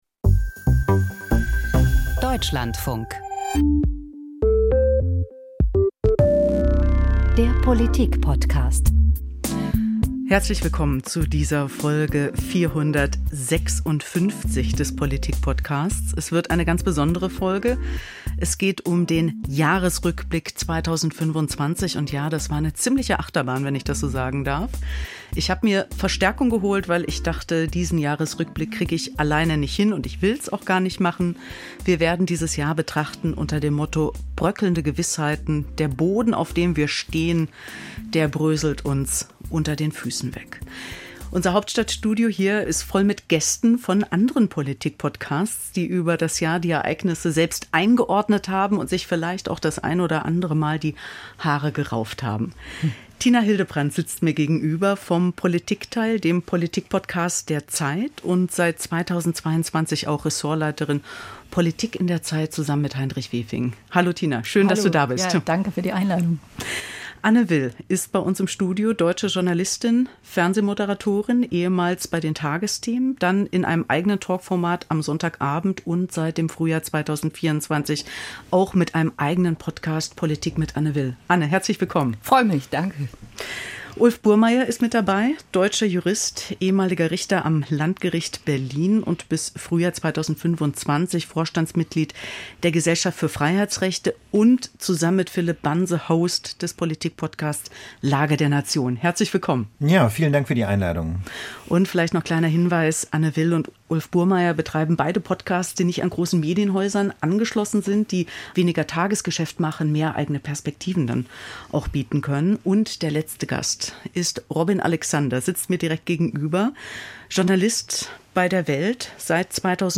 Die Hosts von "Machtwechsel", "Das Politikteil", "Die Lage der Nation" und "Politik mit Anne Will" treffen sich im Politikpodcast zum Jahresrückblick 2025.